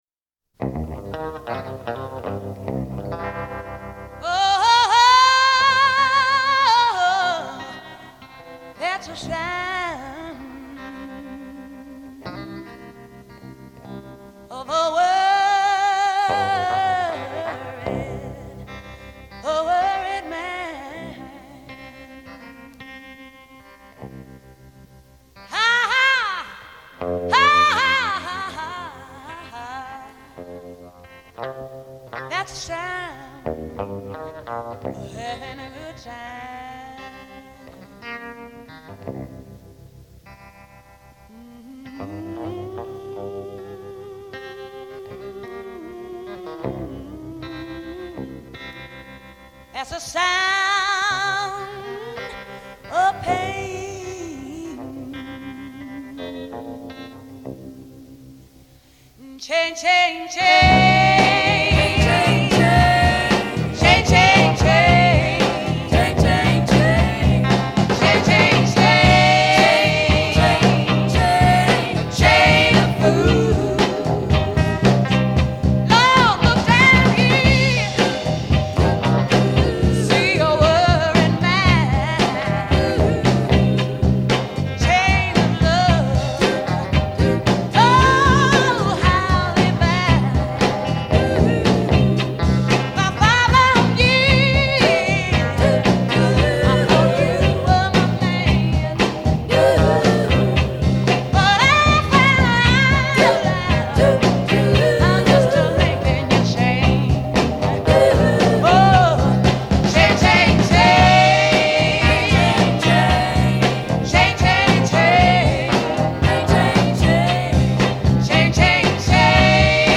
here’s a pitch-corrected file].